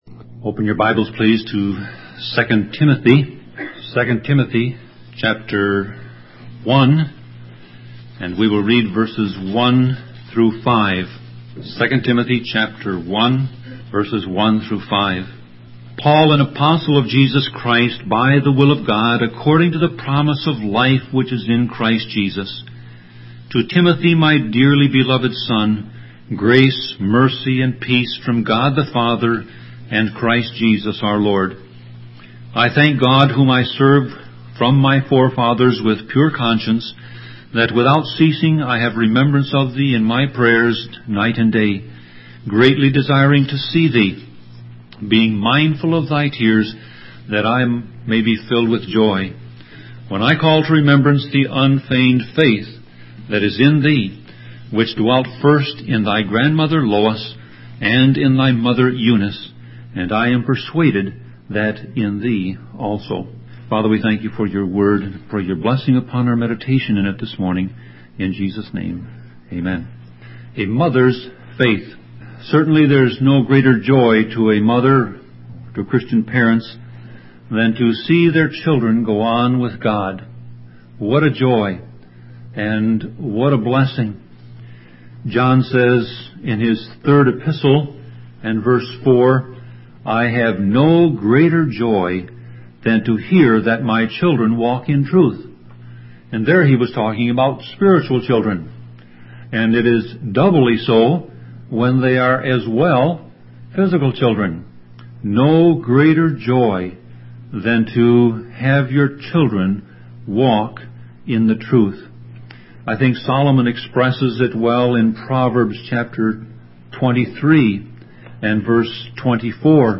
Sermon Audio Passage: 2 Timothy 1:1-5 Service Type